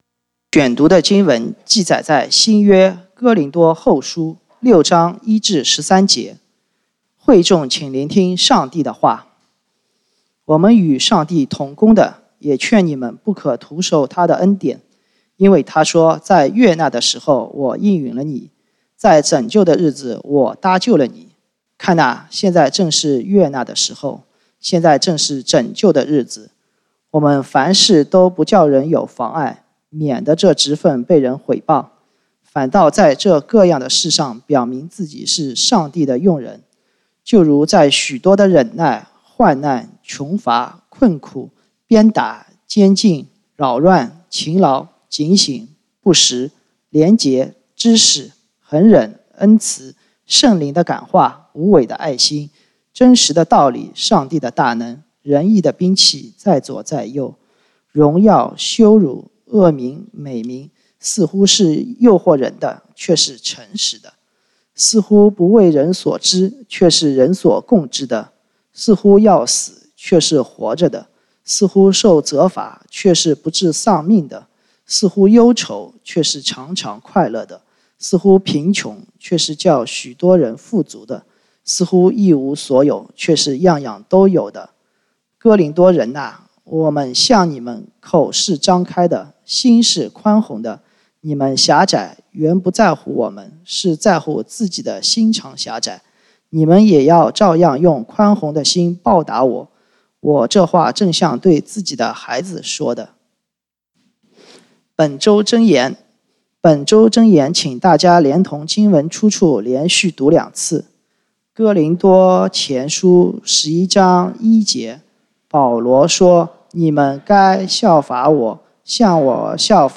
講道經文：《哥林多後書》2 Corinthians 6:1-13